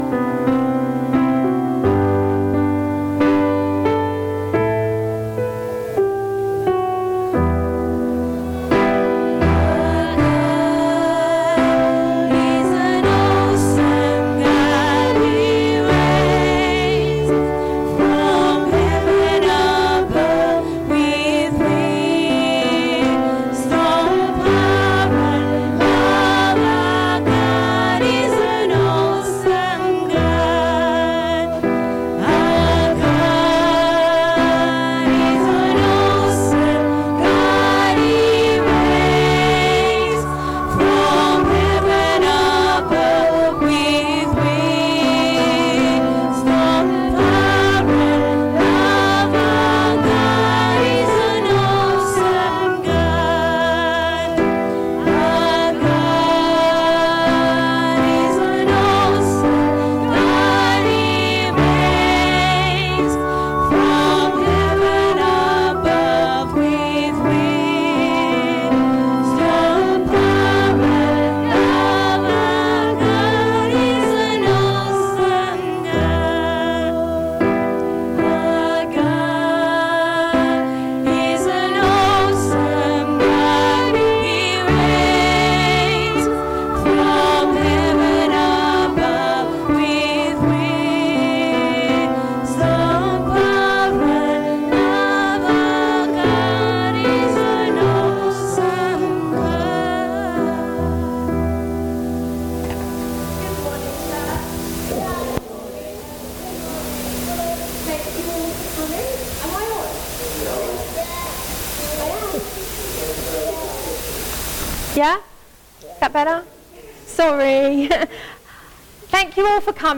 The second in our sermon series 'The Jesus Life.'
Service Audio